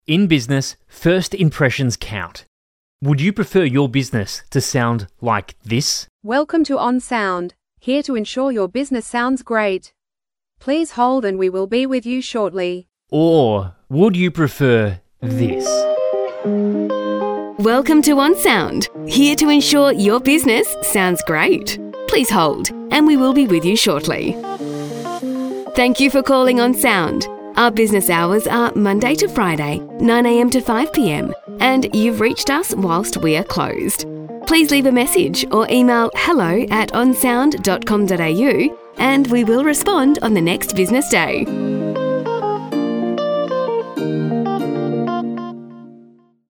Example AI Voiceover Vs Professionally Recorded
AI Voice